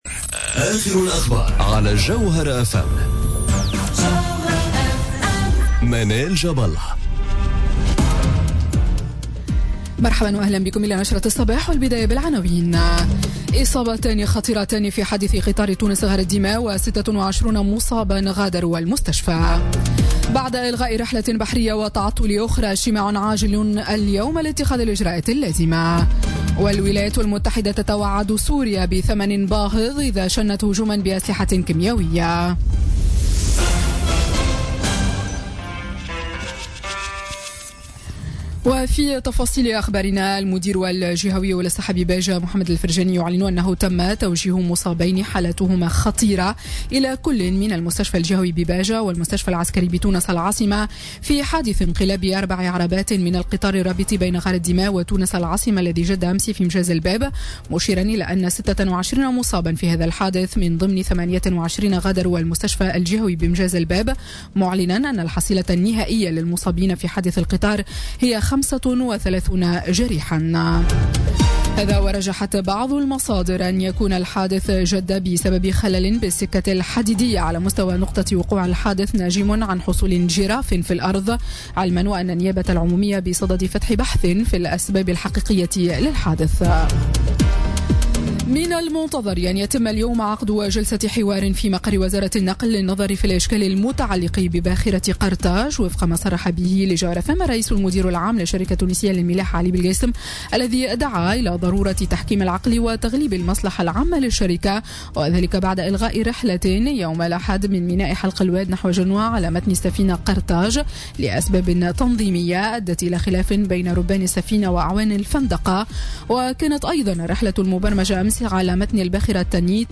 نشرة أخبار السابعة صباحا ليوم الثلاثاء 27 جوان 2017